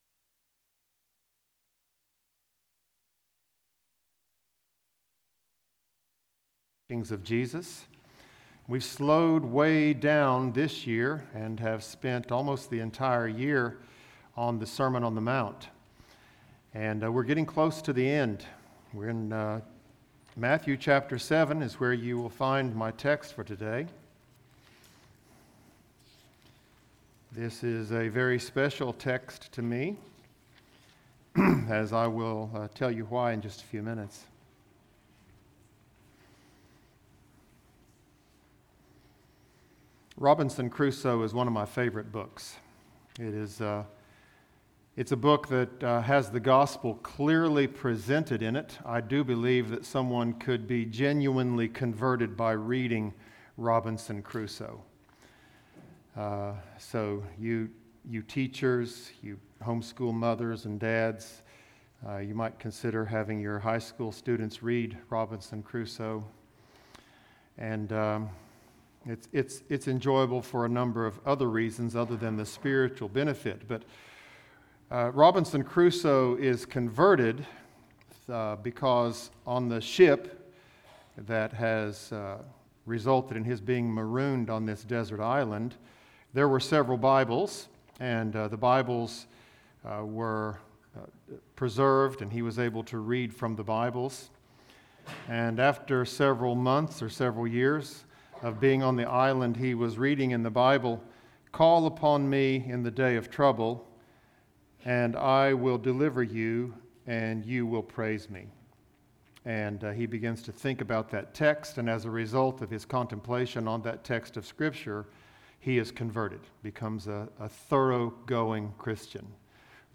Bullitt Lick Baptist Church - Sermons